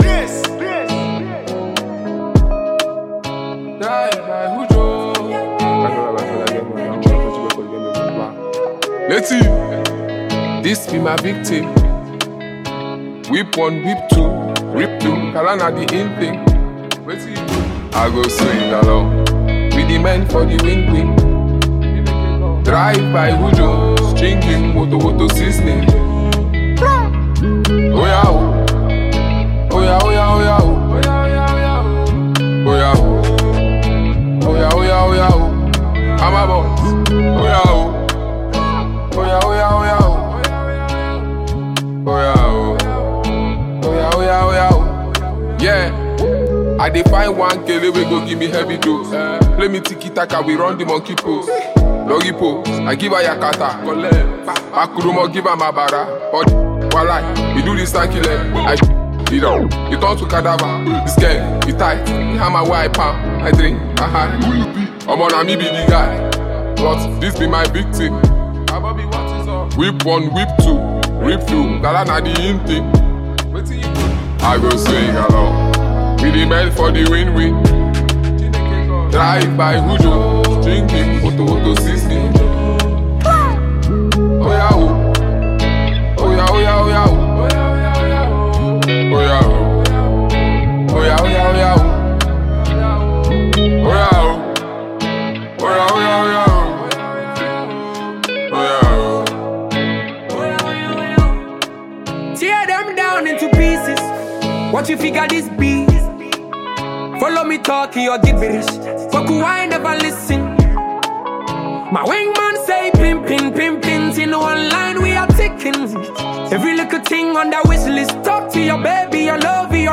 Phenomenon talented Nigerian rap artist and performer
new energizing song